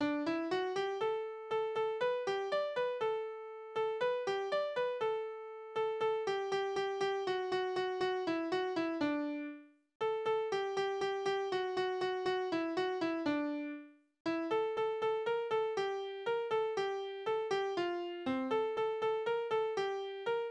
Balladen: Hans im Schornstein
Tonart: D-Dur
Taktart: 4/8
Tonumfang: Oktave
Besetzung: vokal